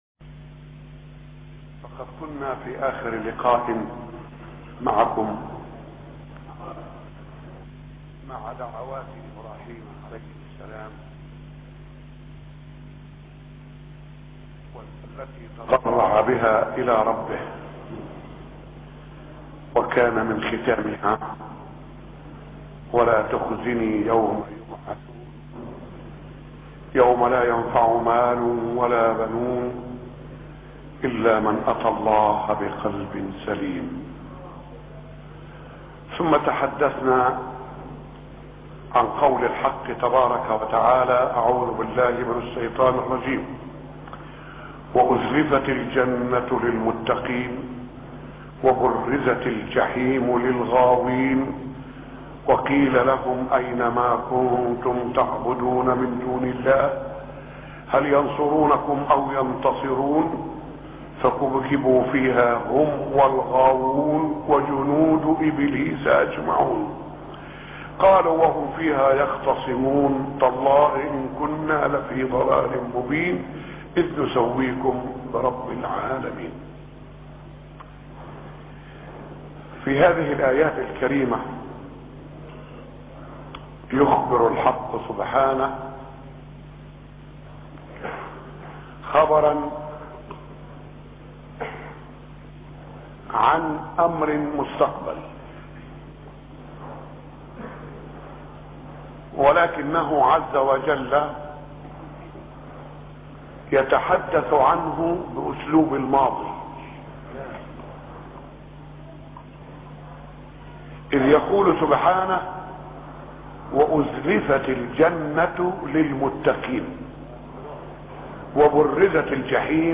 موضوع: سورة الشعراء - مسجد ر.شحاته الشعراء 1-6